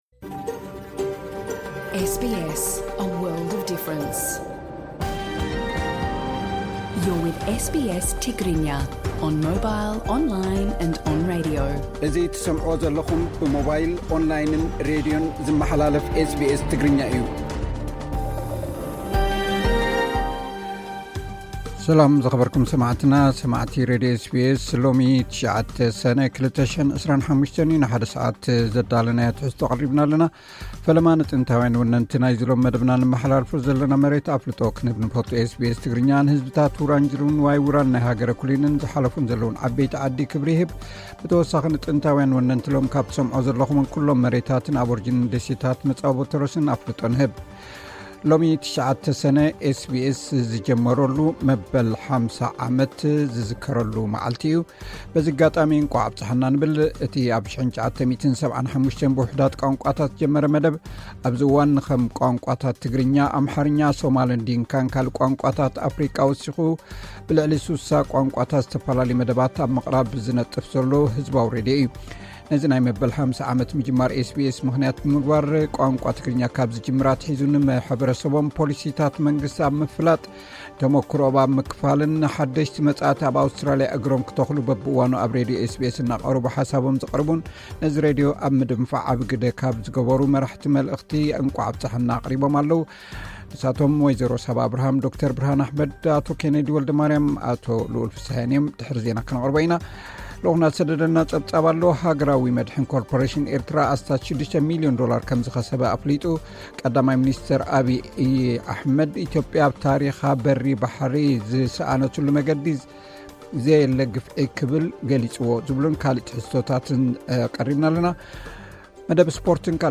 ኣርእስታት ዜና፥